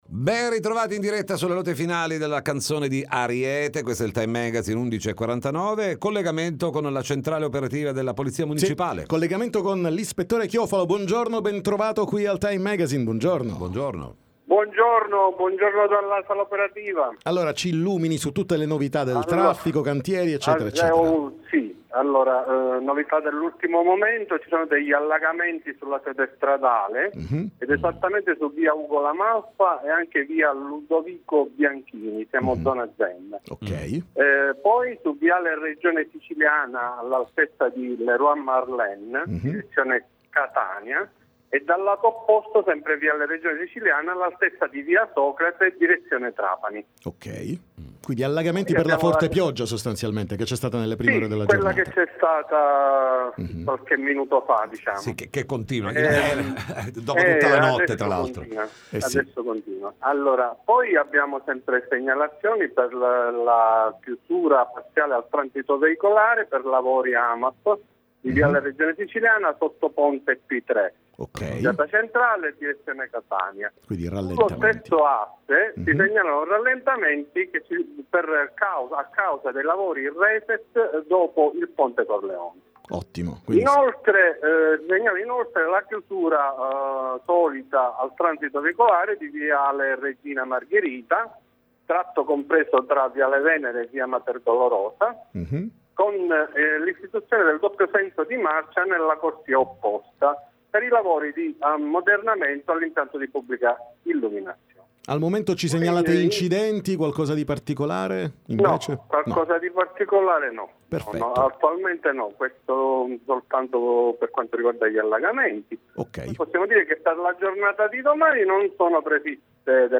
TM Intervista Polizia Municipale